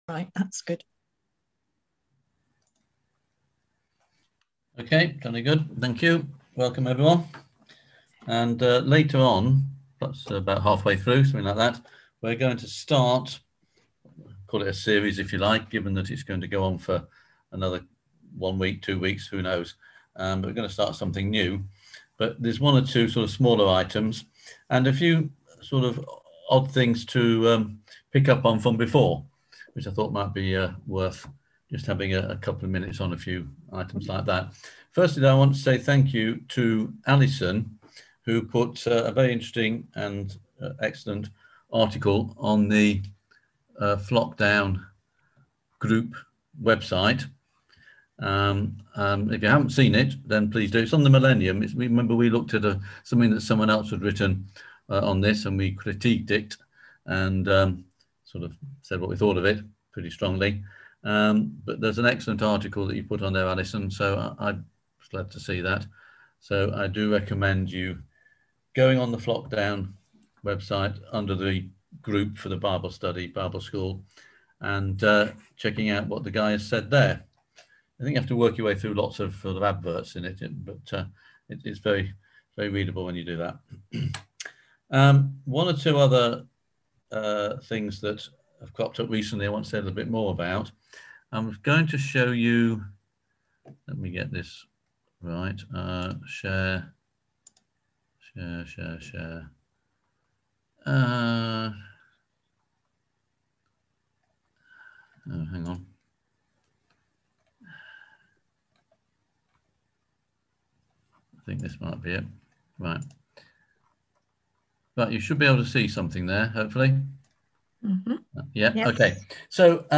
On September 23rd at 7pm – 8:30pm on ZOOM